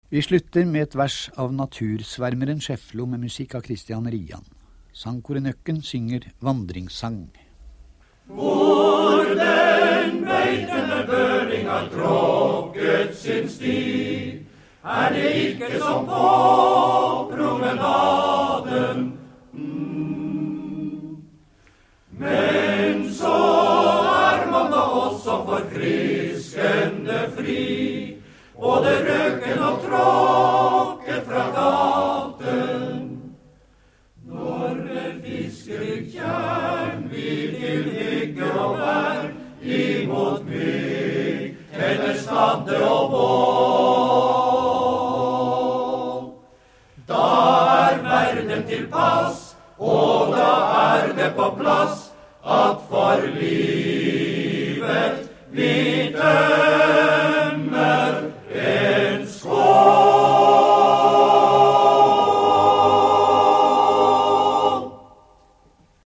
Nøkken live — Mannskoret Nøkken
På hundreårsdagen til Alv Schiefloe – 18. november 1973 – sendte NRK radio et minneprogram om Trondheims store vise- og revyforfatter.